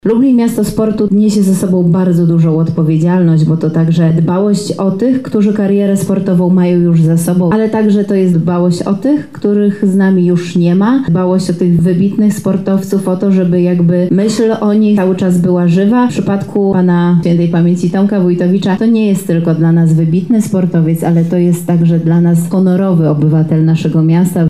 – mówi Beata Stepaniuk-Kuśmierzak, Zastępca Prezydenta Lublina ds. Kultury, Sportu i Partycypacji.